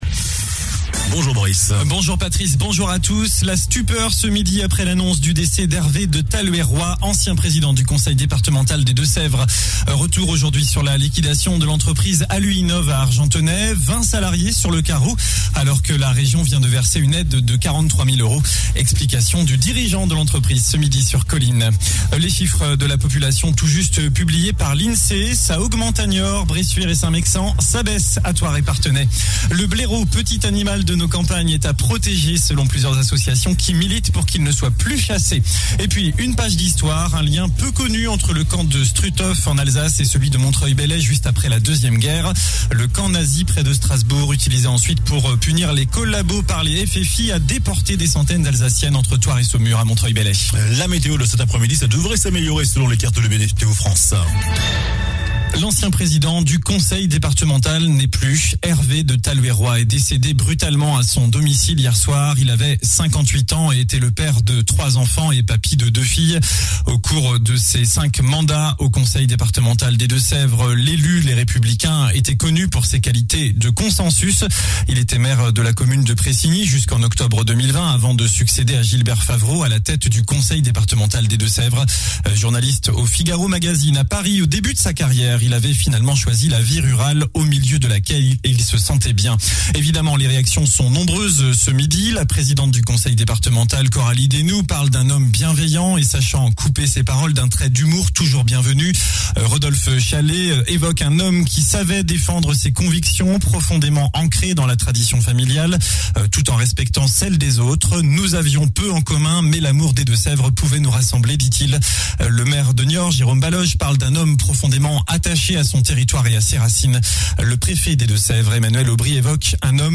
JOURNAL DU JEUDI 30 DECEMBRE ( MIDI )